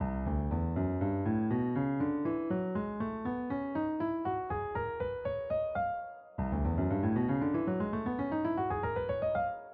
LYDIAN SCALIC/MODAL EXTENSION GOING UP IN THE CYCLE OF 5THS STARTING with “C lydian”